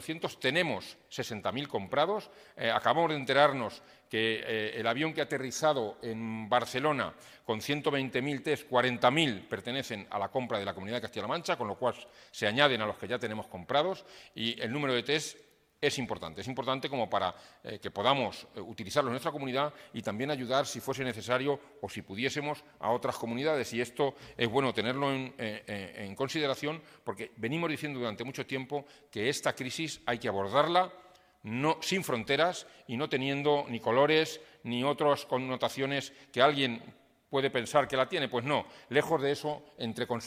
Un total de 63 pacientes afectados por Covid-19 han sido ya extubados en las unidades de críticos de los hospitales de Castilla-La Mancha desde que se inició la emergencia sanitaria, tal y como ha informado hoy el consejero de Sanidad, Jesús Fernández Sanz, durante la rueda de prensa ofrecida esta mañana.